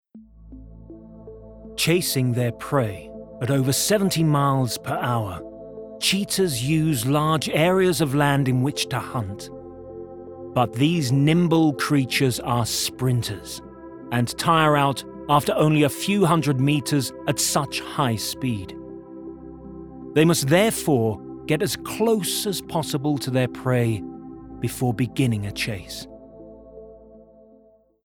UK